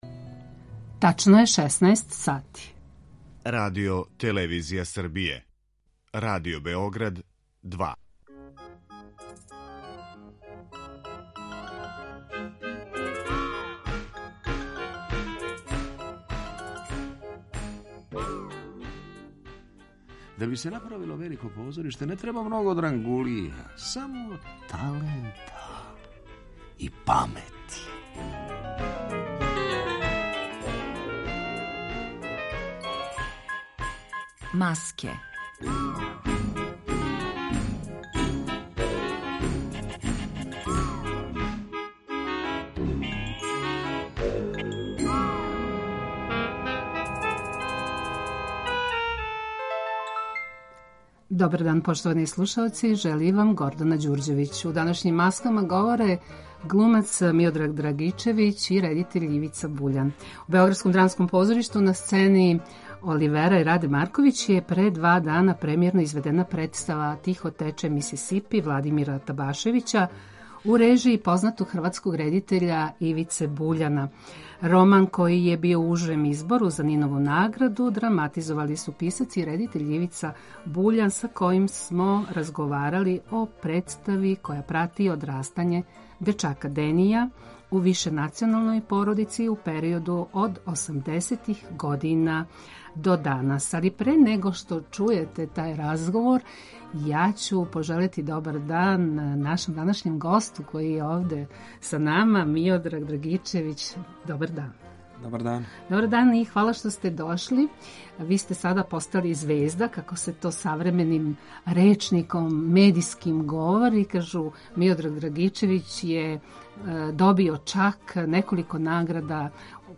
Све епизоде серијала Аудио подкаст Радио Београд 2 Јозеф Јонген Колаж Сленг